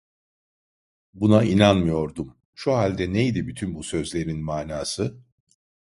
Pronounced as (IPA) /ʃu/